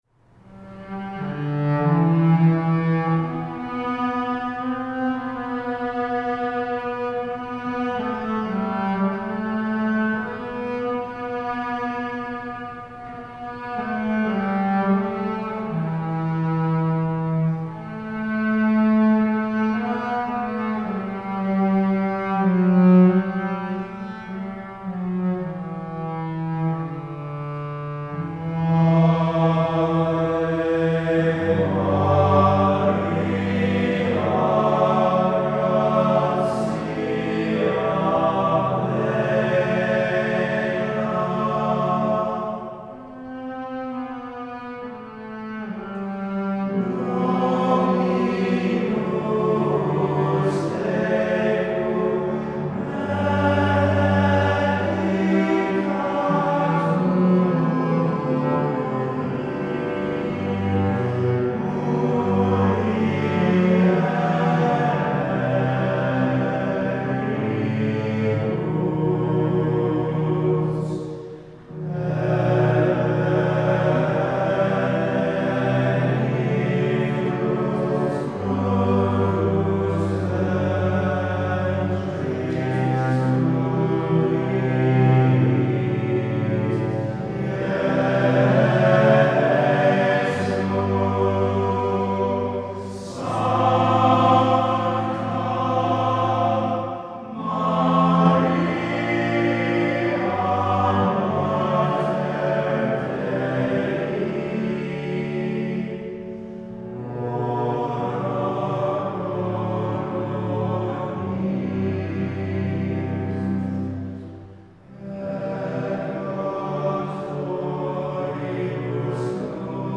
Gregorian Chant and Polyphony From Gregory the Great Academy’s Highlander Schola Cantorum.